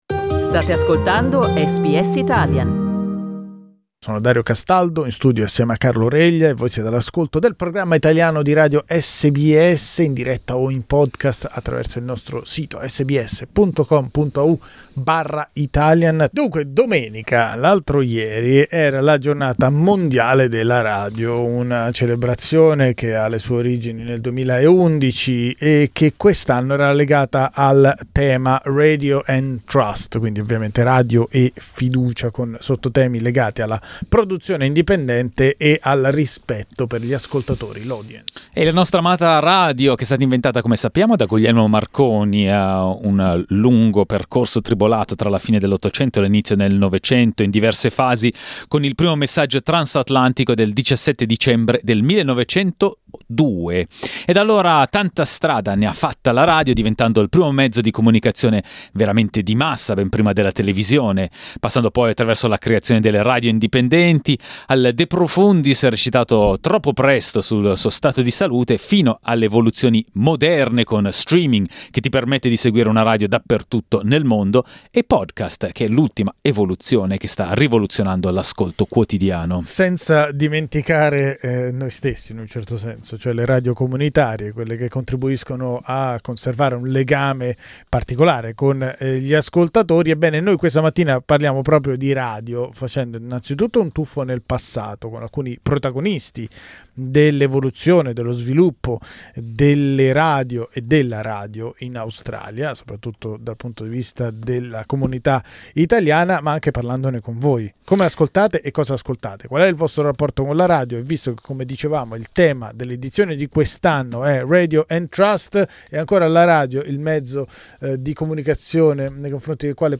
Noi lo abbiamo chiesto ad ascoltatori e ascoltatrici e alcuni ospiti che sono intervenuti in diretta.